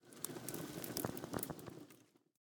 Minecraft Version Minecraft Version snapshot Latest Release | Latest Snapshot snapshot / assets / minecraft / sounds / block / vault / ambient2.ogg Compare With Compare With Latest Release | Latest Snapshot
ambient2.ogg